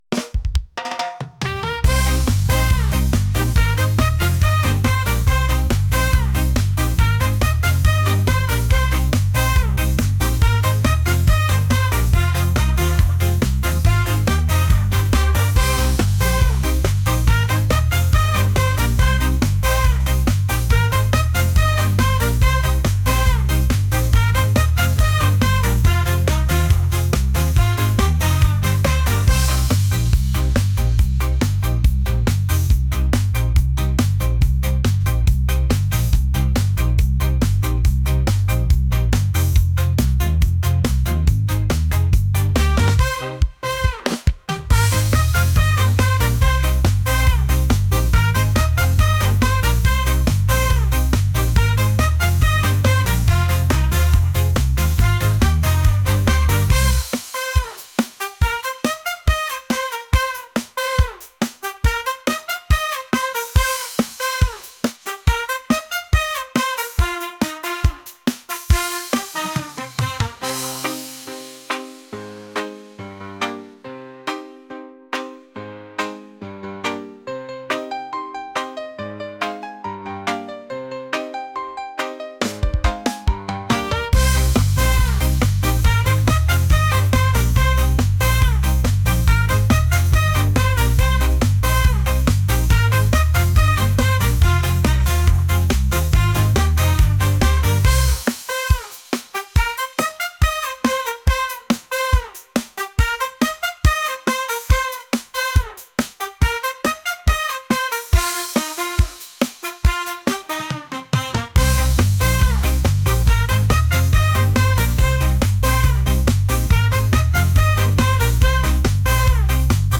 electronic | reggae | pop